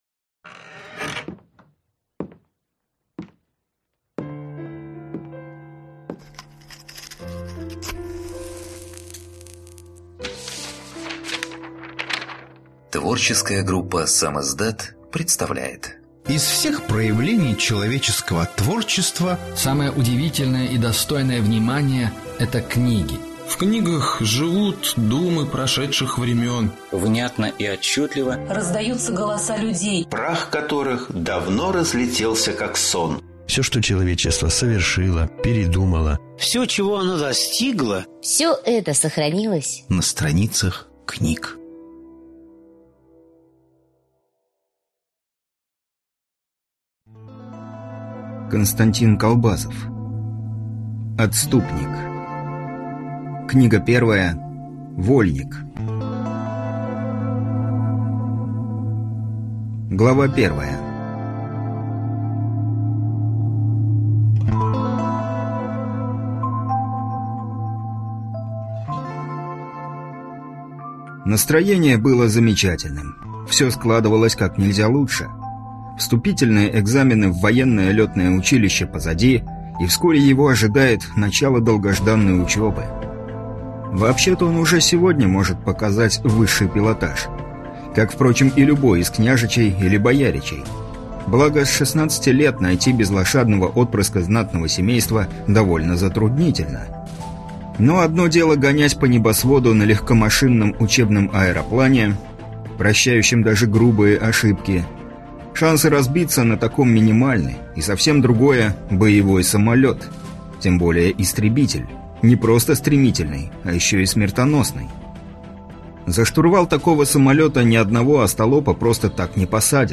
Аудиокнига Отступник. Вольник | Библиотека аудиокниг
Прослушать и бесплатно скачать фрагмент аудиокниги